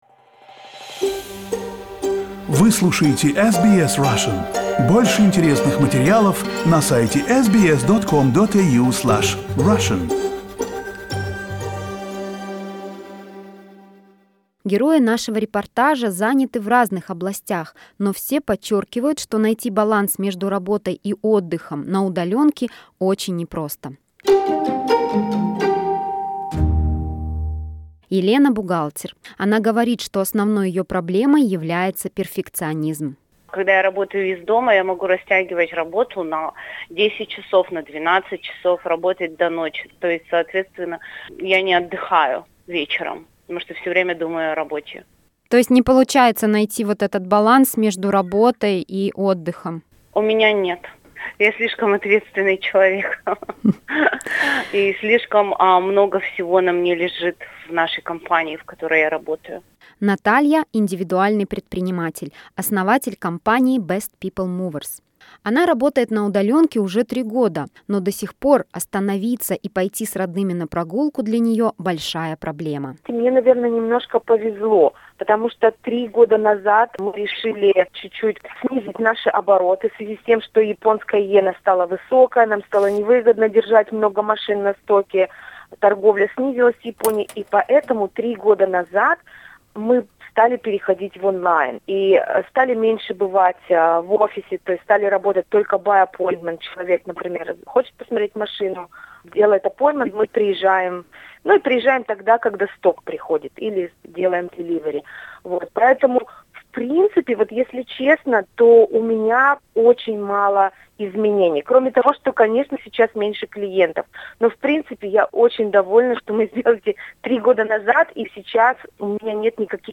Interviews with people and professional advice from a psychologist.